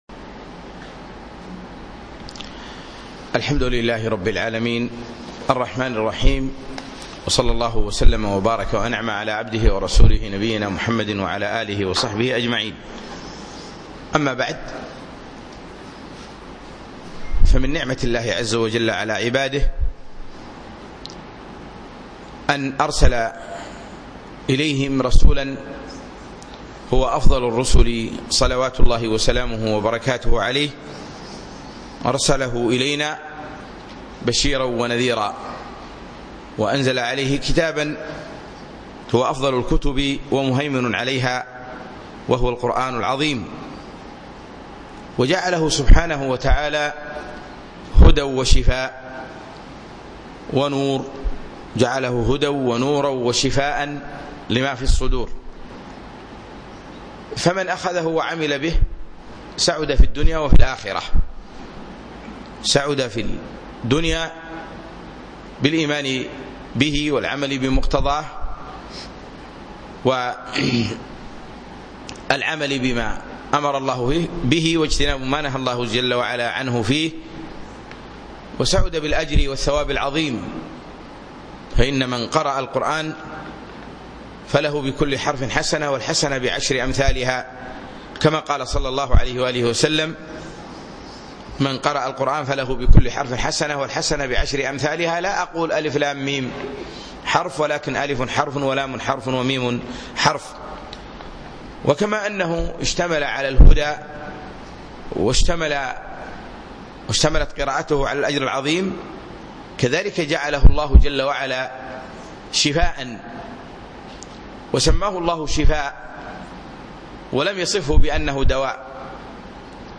الإستشفاء بالقرآن محاضرة بدولة الكويت